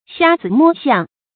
注音：ㄒㄧㄚ ㄗㄧ ㄇㄛ ㄒㄧㄤˋ
瞎子摸象的讀法